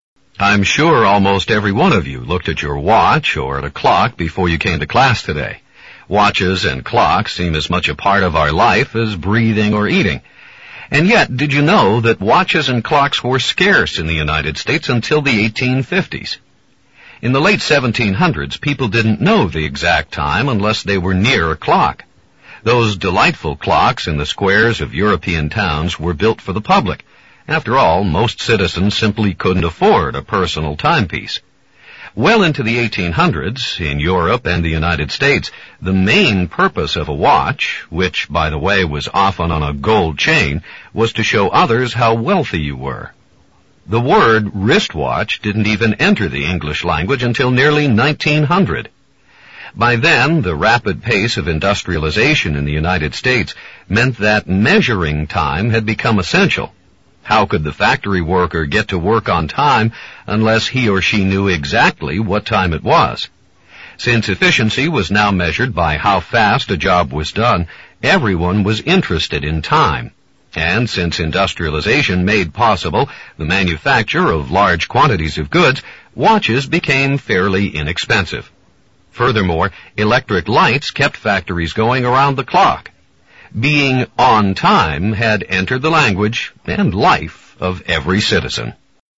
You will hear a short lecture.